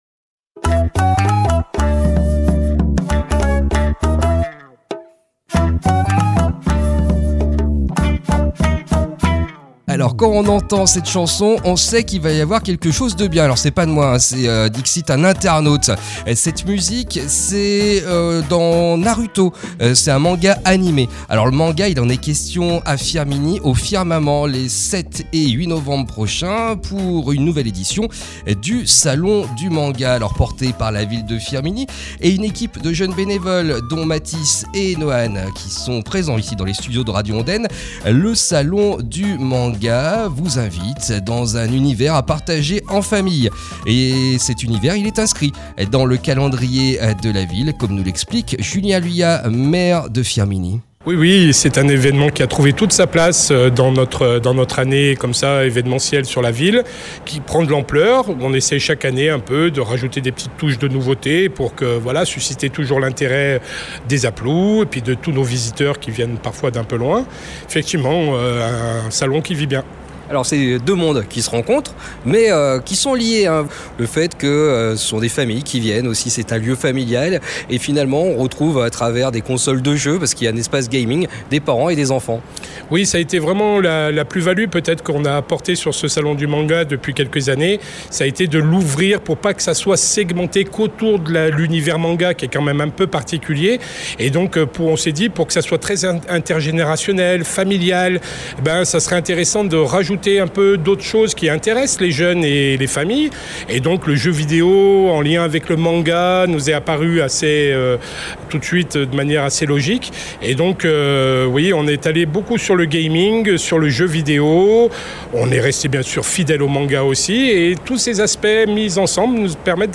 Julien Luya, maire de Firminy
jeunes bénévoles organisateurs